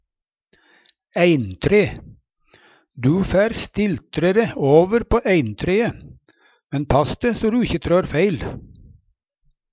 eintre - Numedalsmål (en-US)